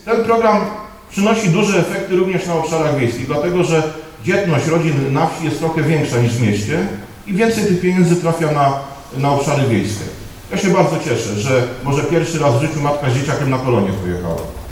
Podczas wczorajszego spotkania z rolnikami w Barzkowicach poseł Prawa i Sprawiedliwości, Jan Krzysztof Ardanowski chwalił flagowe programy swojej partii.